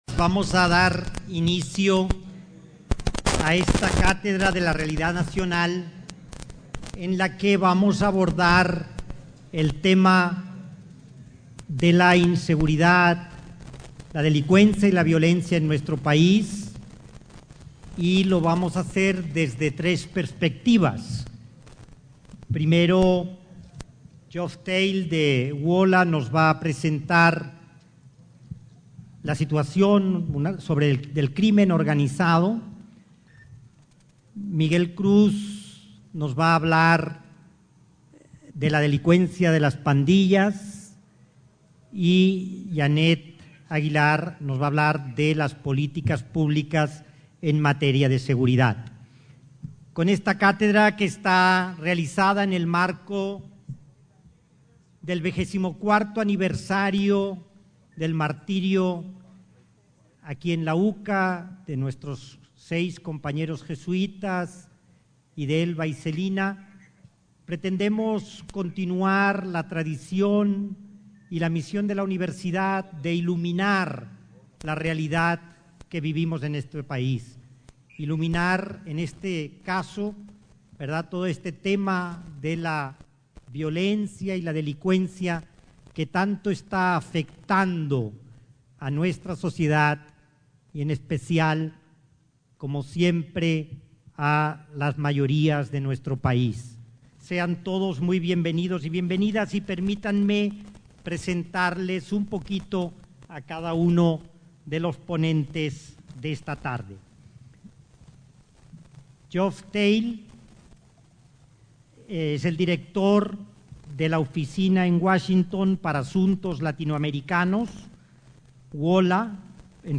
El 12 de noviembre, en el Auditorio “Ignacio Ellacuría”, se desarrolló la tradicional Cátedra de Realidad Nacional. El tema de análisis fue la situación de inseguridad, específicamente lo relacionado con las pandillas y el crimen organizado.